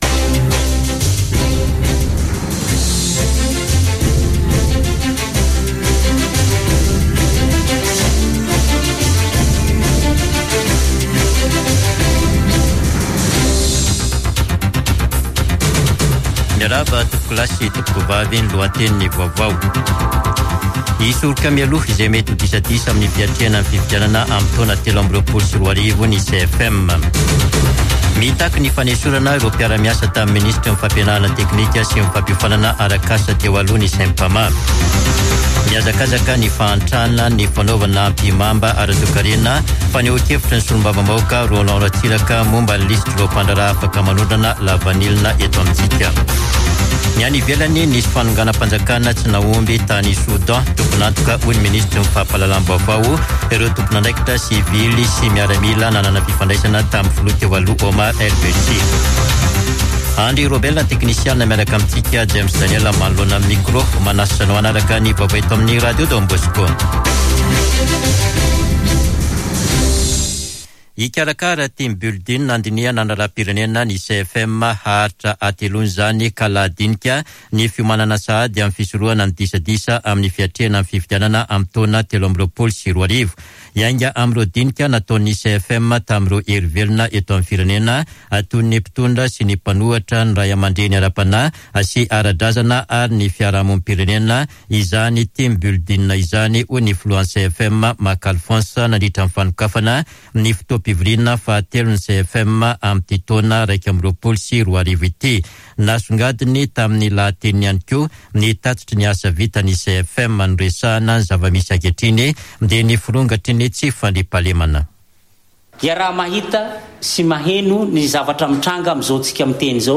[Vaovao hariva] Talata 21 septambra 2021